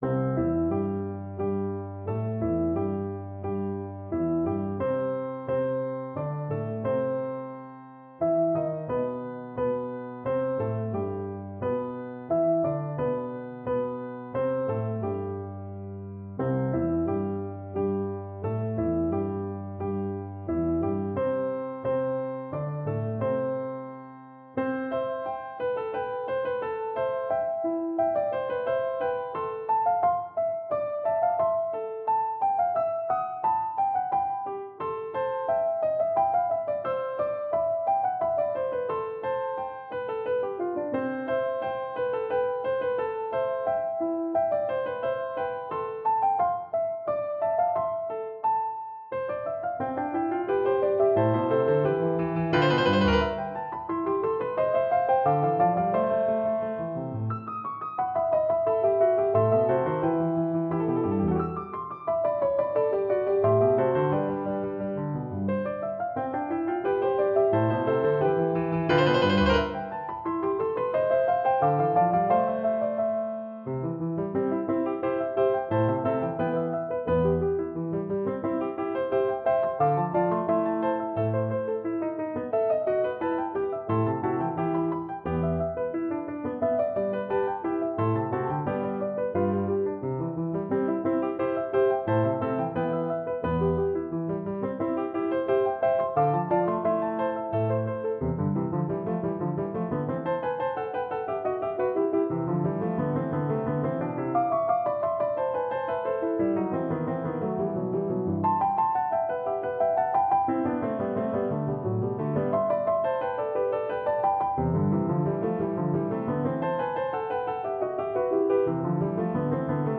III. Andante con moto
» 442Hz